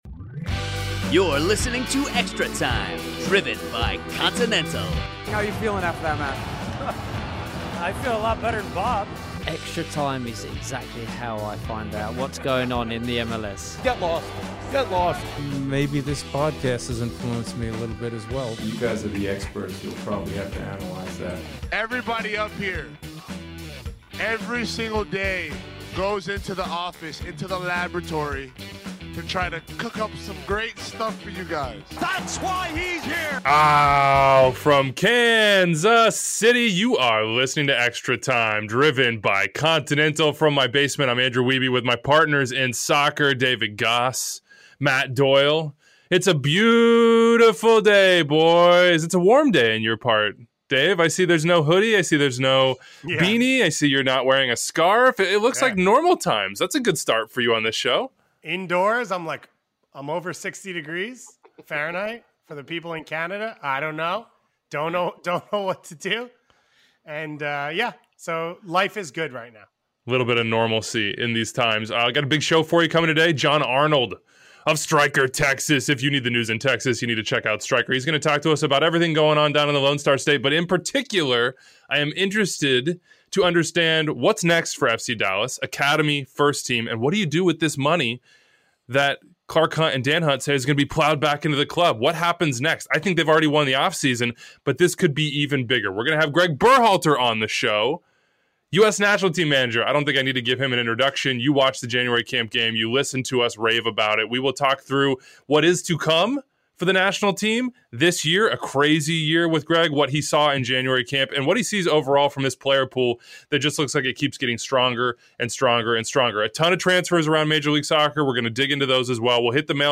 Gregg Berhalter calls in to talk USMNT progress, respect in Europe & busy 2021
USMNT manager Gregg Berhalter joins the Extratime guys to talk about the strengths of the player pool, growing respect for Americans in Europe, golden generation vs. first wave and misinterpretations of the False 9.